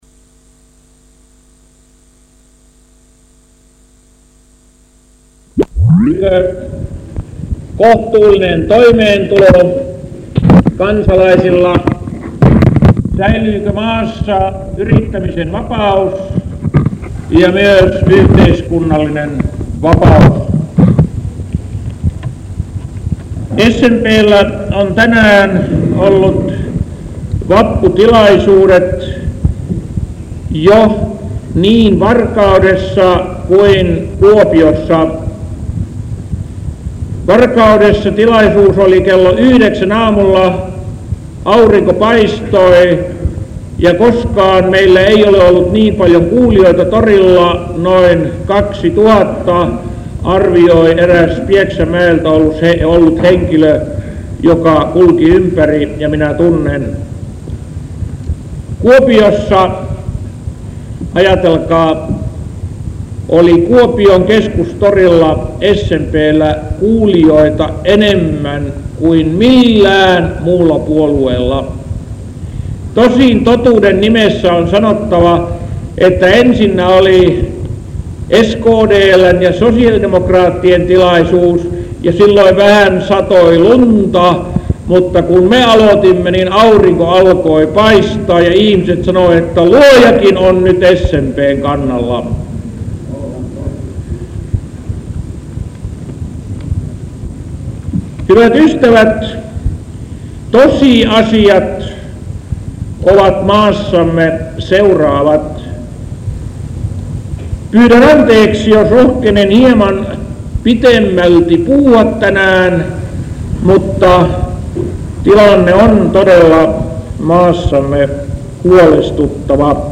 Veikko Vennamon puhe vuodelta 1974.
vennamonpuhe_vappu_1974.mp3